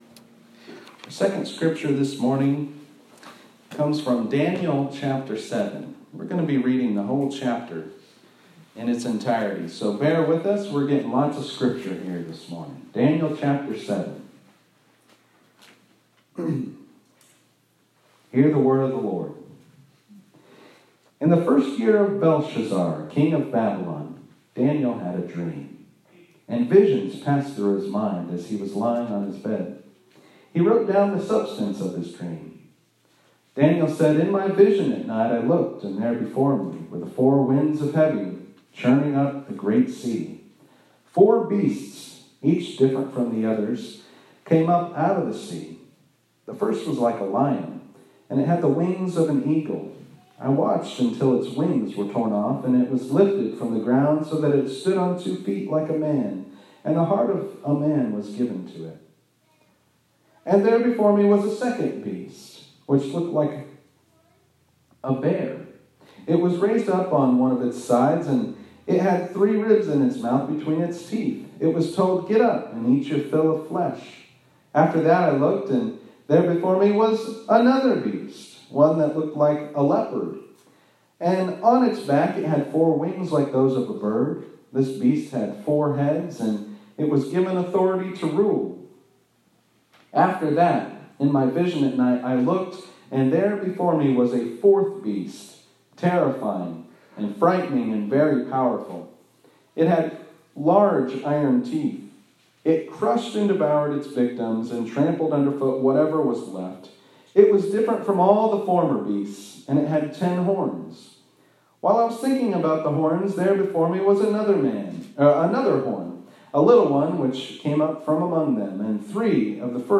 This sermon was preached at Mt. Gilead UMC in Georgetown, KY on Oct 4, 2020.